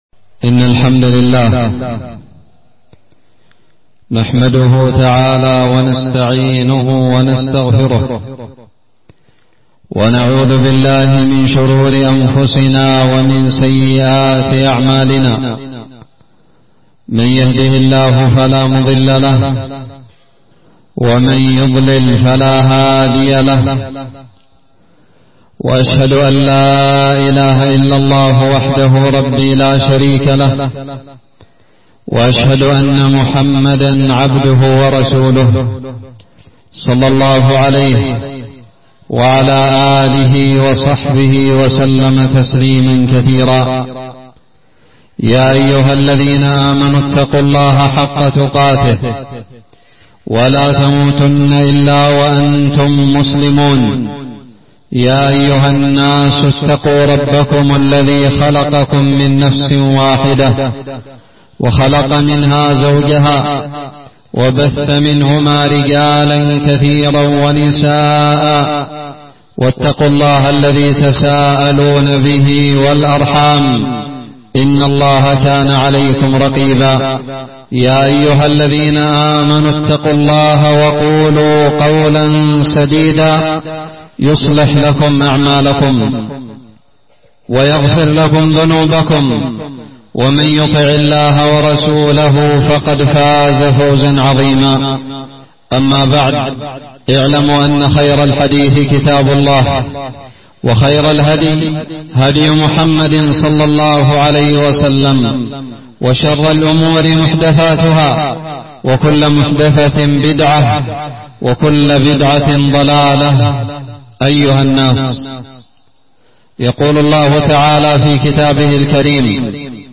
خطب الجمعة
ألقيت بدار الحديث السلفية للعلوم الشرعية بالضالع في 16 ربيع الآخر 1441هــ